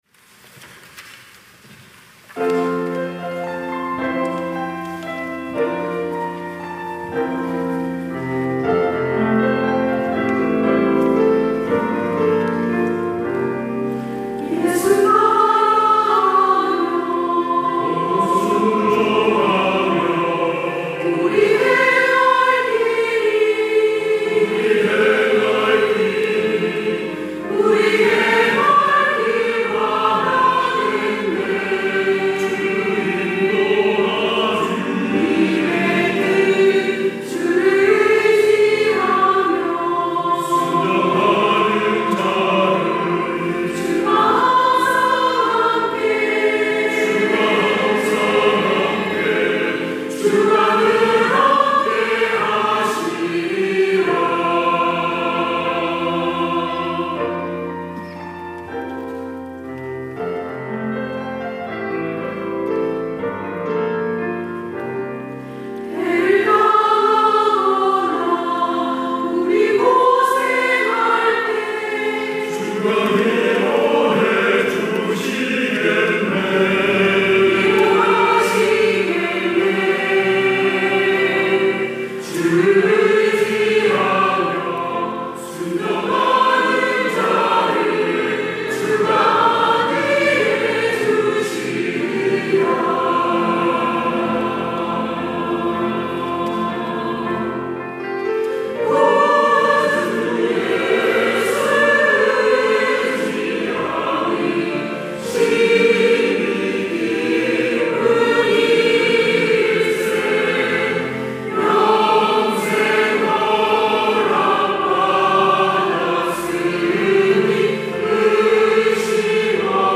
찬양대 가브리엘